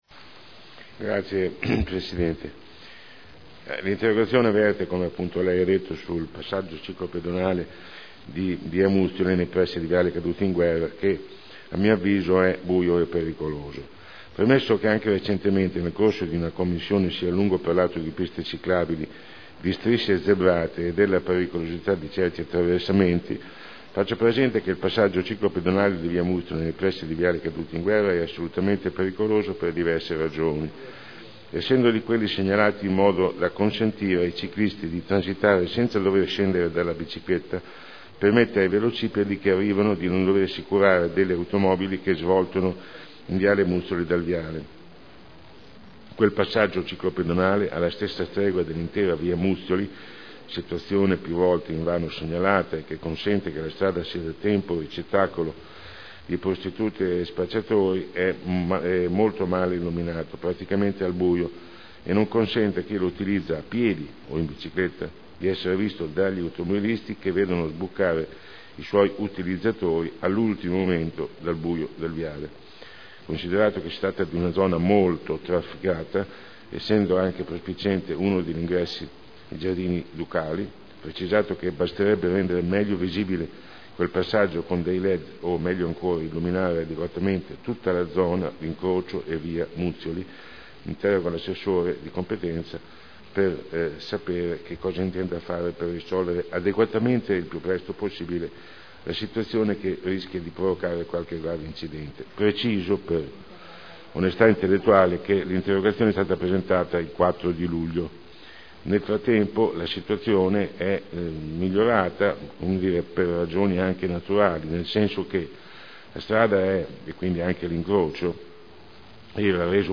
Sandro Bellei — Sito Audio Consiglio Comunale